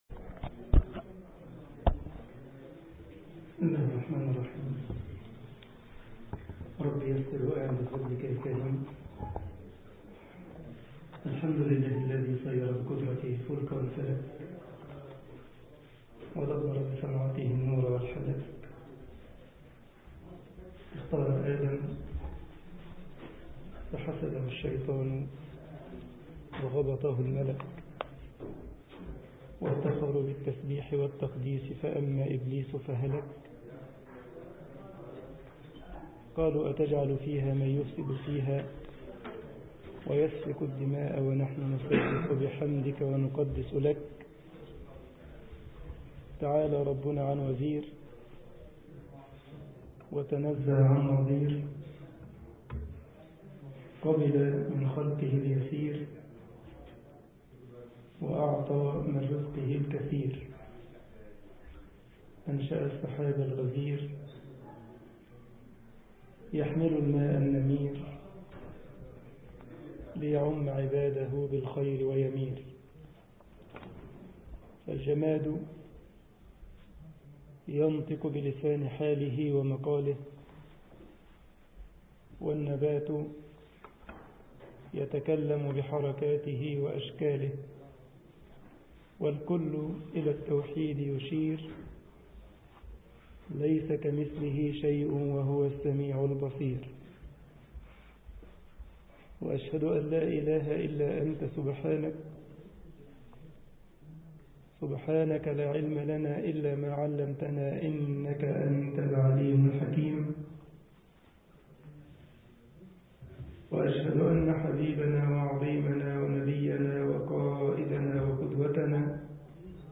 مسجد الجمعية الإسلامية بكايزرسلاوترن ـ ألمانيا